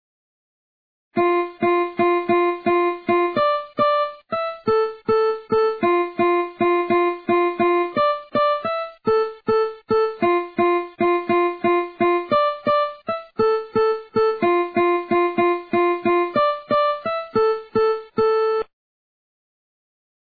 Question Need ID plz, some Progressive!
hehe, nice piano riff